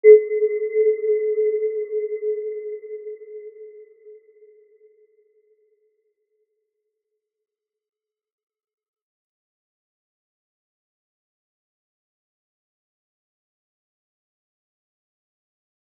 Aurora-B4-mf.wav